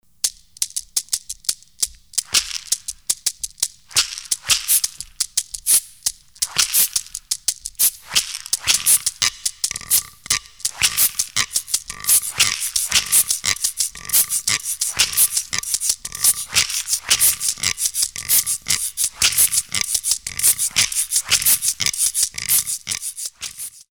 Cd of African rhythms
9 percussion tracks  - some vocals